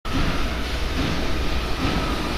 dumpingOre.wav